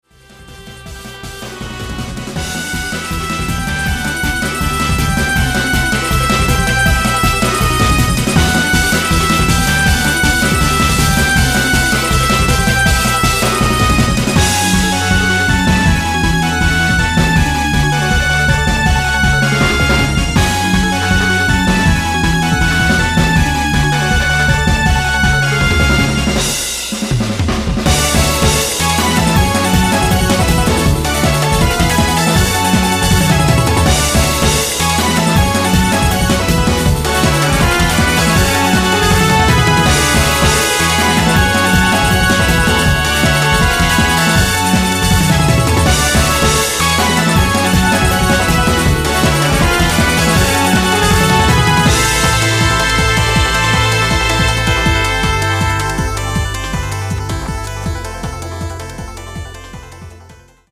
Track 1-11 ... リマスタリングver